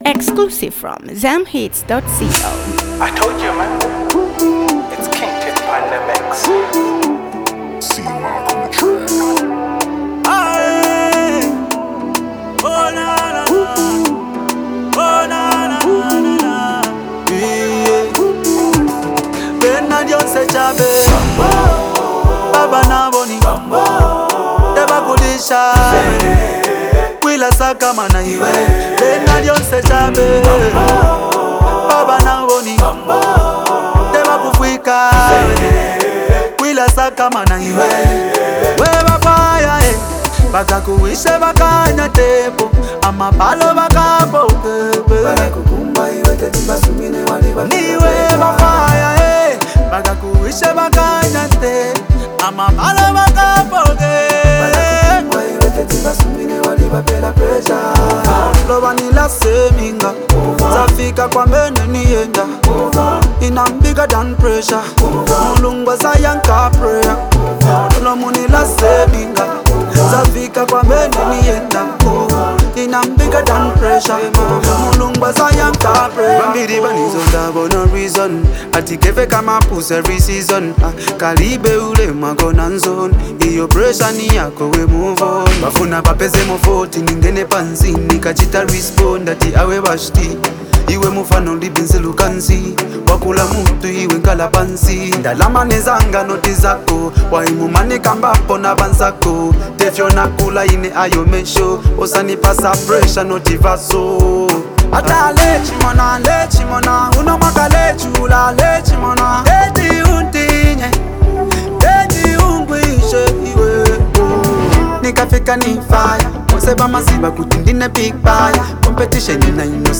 a powerful and emotional track